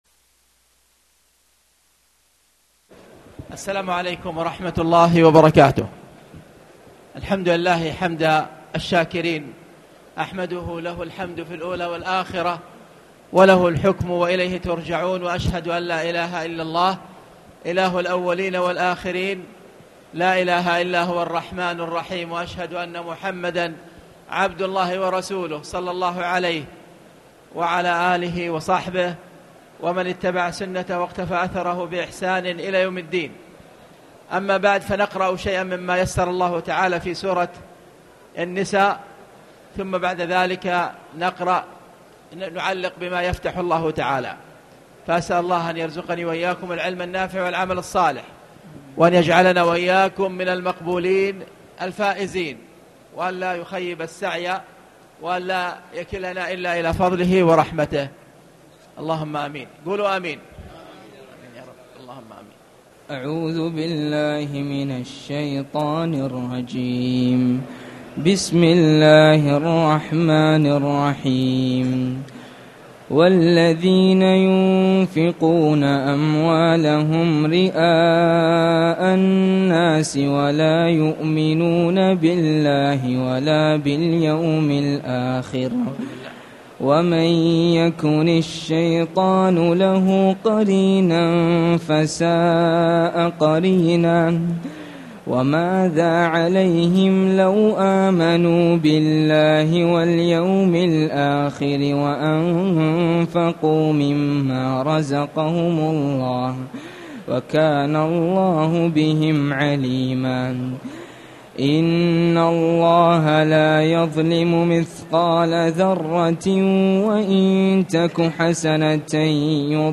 تاريخ النشر ٢٩ رمضان ١٤٣٨ هـ المكان: المسجد الحرام الشيخ